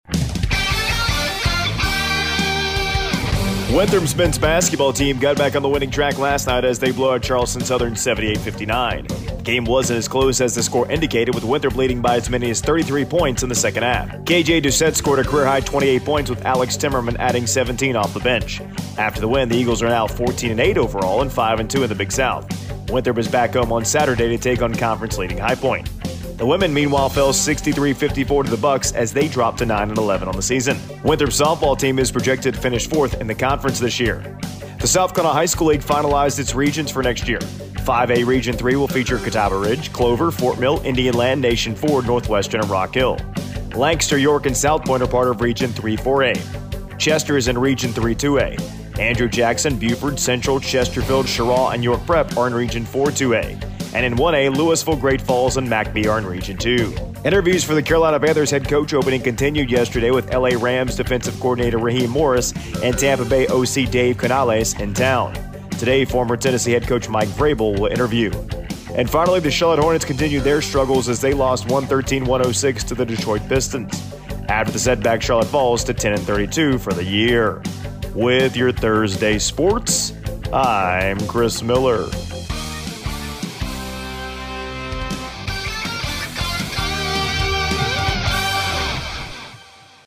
AUDIO: Wednesday Morning Sports Report